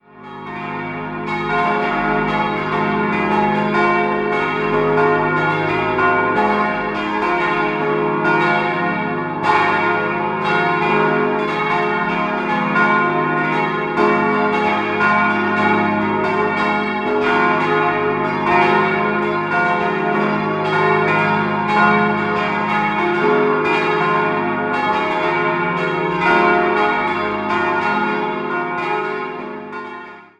5-stimmiges Geläut: as°-es'-f'-as'-b' Die große Glocke wurde im Jahr 1938 von Franz Schilling in Apolda gegossen.